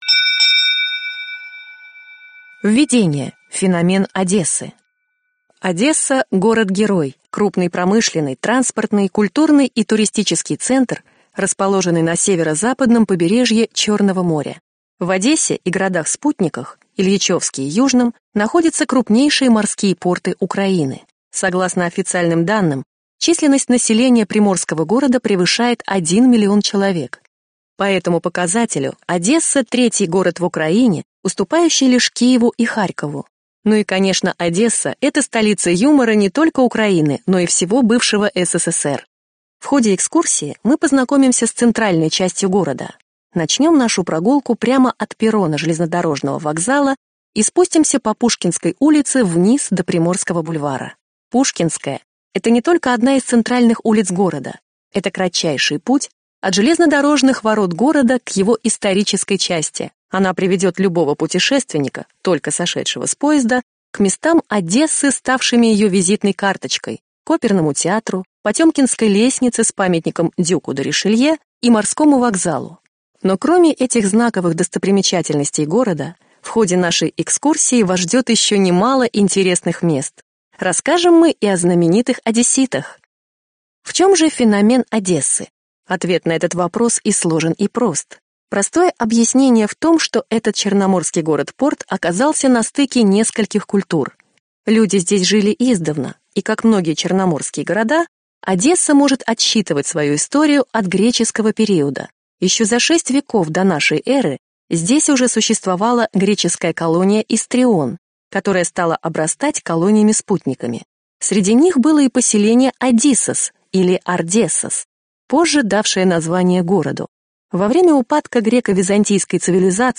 Аудиокнига Одесса | Библиотека аудиокниг